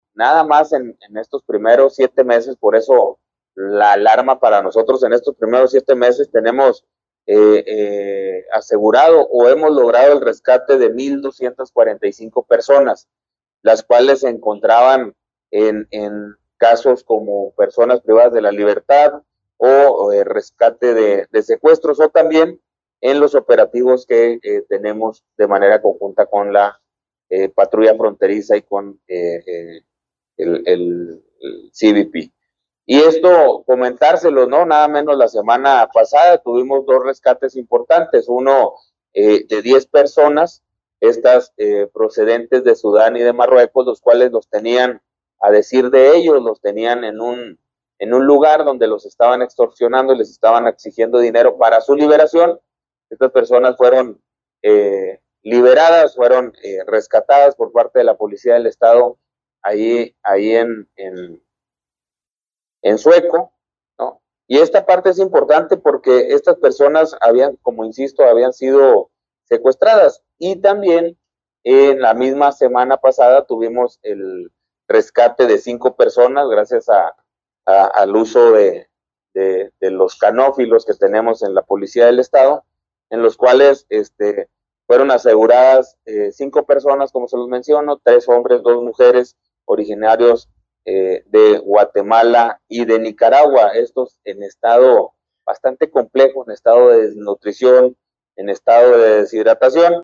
AUDIO: GILBERTO LOYA CHAVÉZ, SECRETARIO DE SEGURIDAD PÚBÑICADEL ESTADO (SSPE) 2